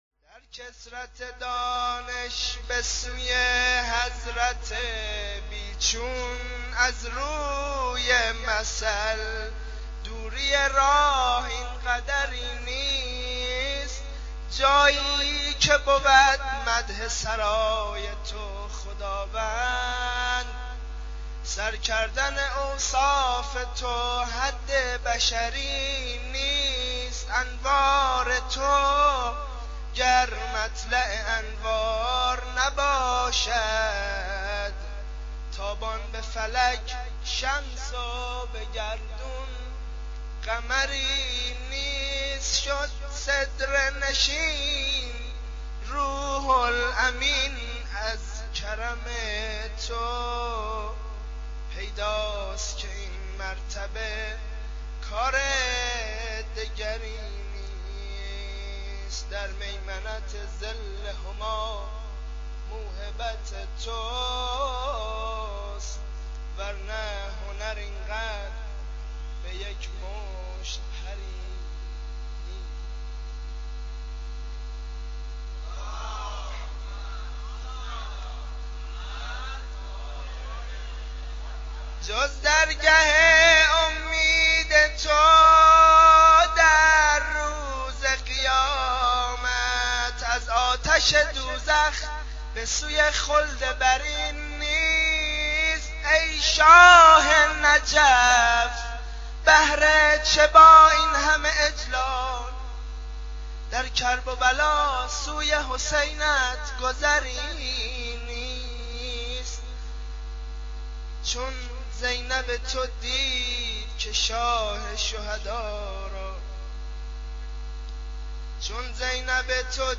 جشن ولادت امام علی (ع)؛ مدح